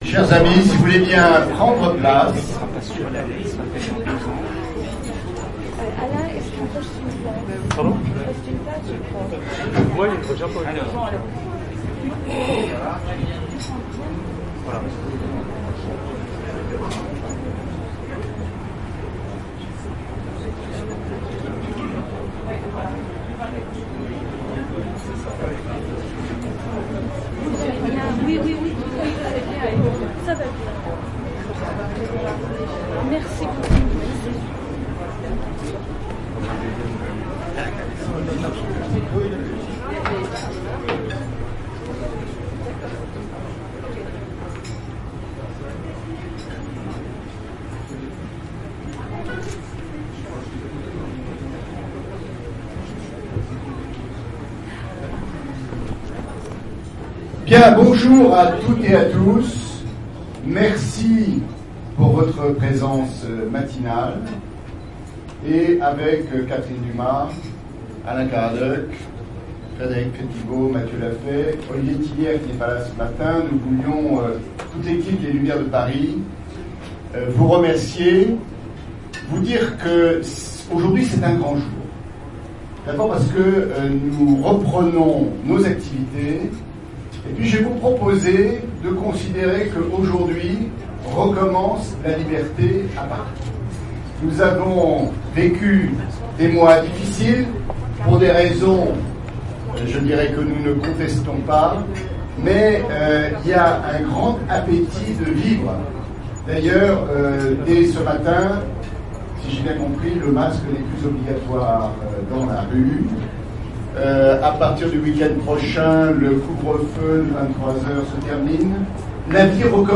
Les Lumières de Paris - Institut International a repris son activité événementielle (en présentiel) en recevant un invité exceptionnel, qui a officié au plus haut niveau dans les cuisines de la Présidence de la République, à l’Elysée, pendant 25 ans et pour 4 Chefs de l’État : Guillaume GOMEZ.